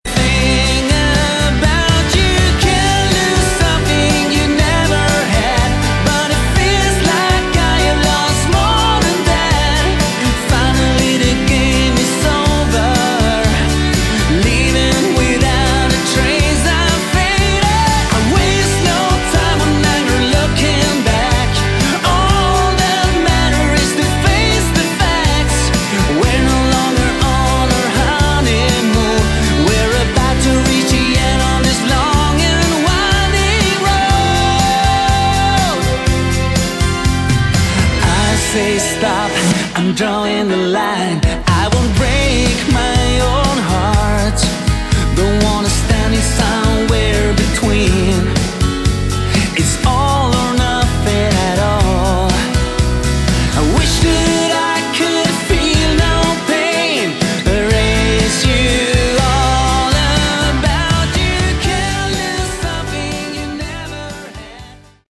subtle Hard Rock/AOR album
genuine and original Melodic hard rock with AOR flavour